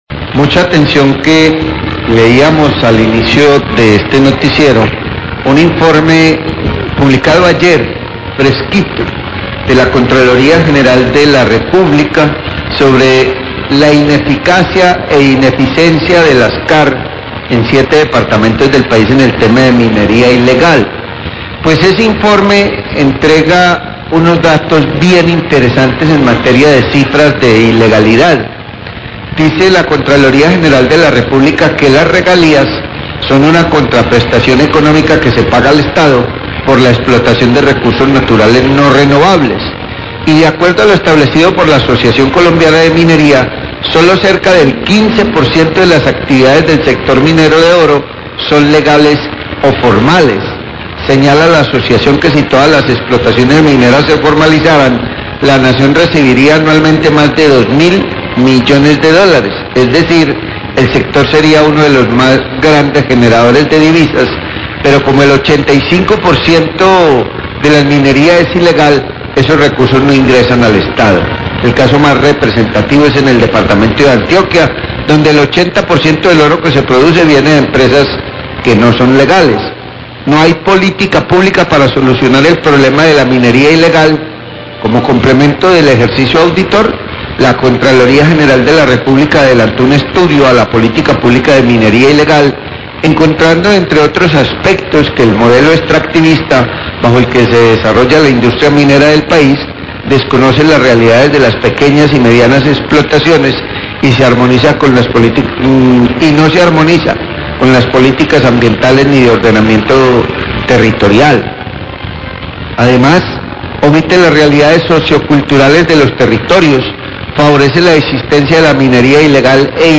Radio
Periodistas señalan que están cansados de la inoperancia de la CVC, dicen que persiguen a los que queman un bulto de carbón y no a las multinacionales y las grandes mafias de la explotación minera son pasadas por alto.